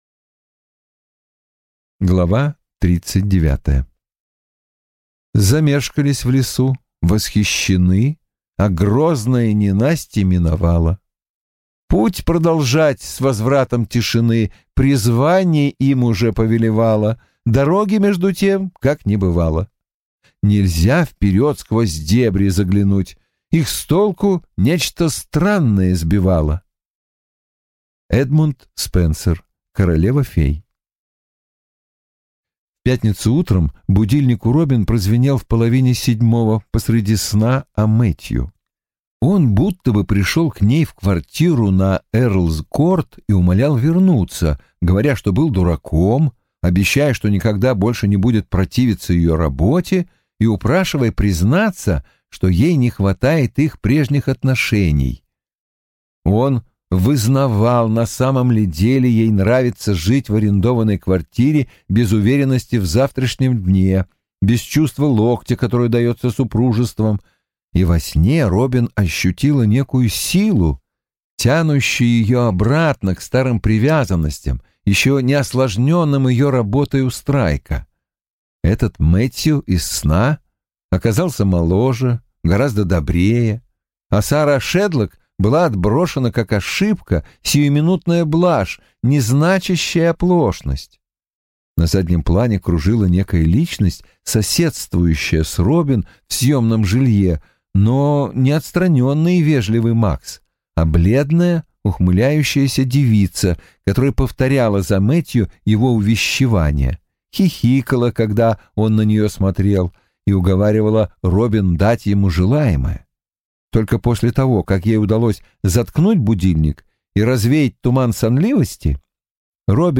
Аудиокнига Дурная кровь. Книга 2 | Библиотека аудиокниг